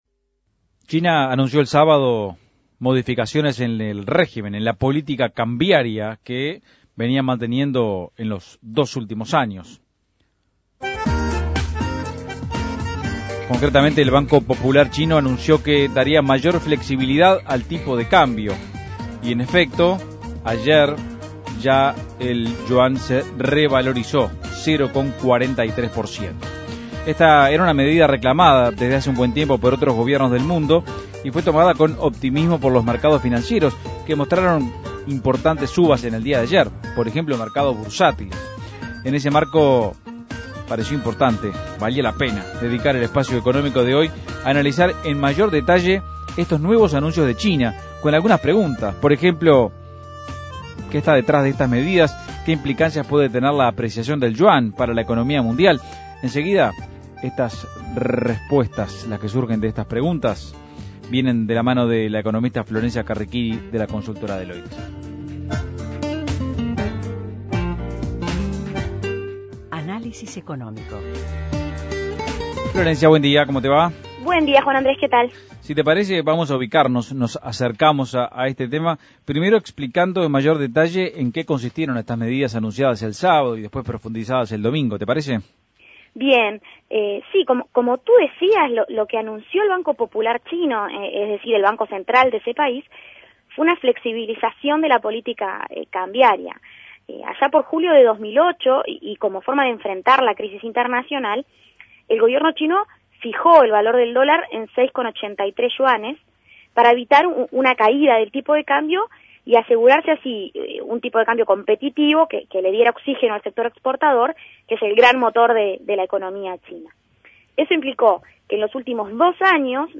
Análisis Económico El Banco Popular de China anunció una flexibilización de la política cambiaria